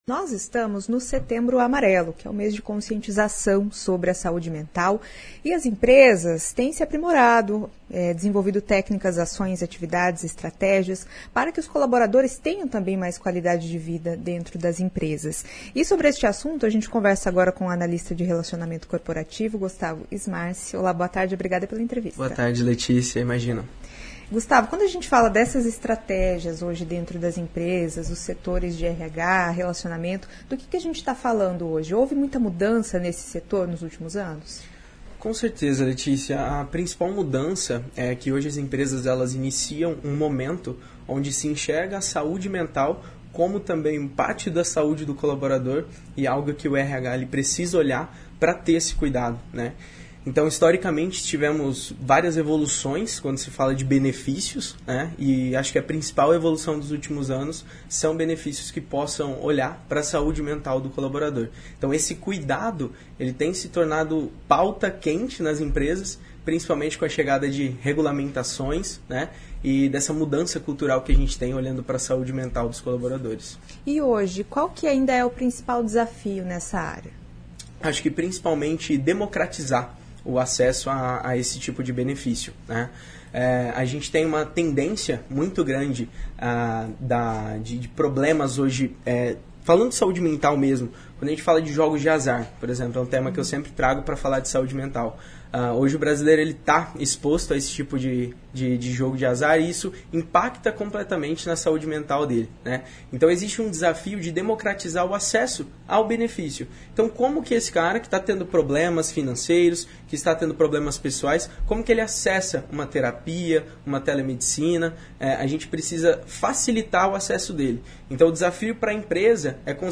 Entrevista com o analista de relacionamento corporativo